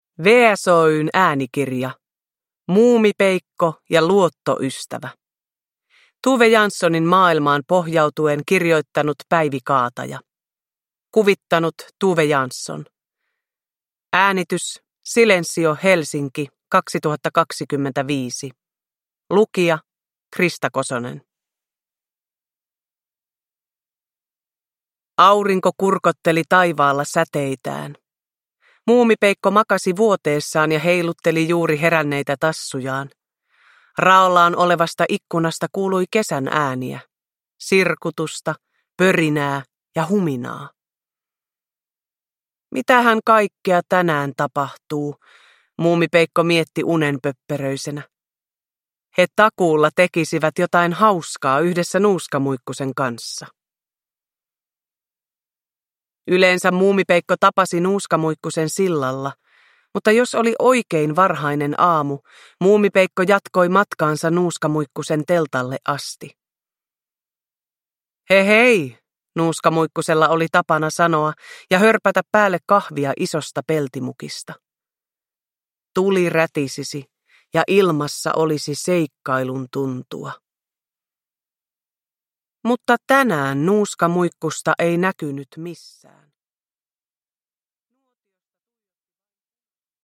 Muumipeikko ja luottoystävä – Ljudbok
Uppläsare: Krista Kosonen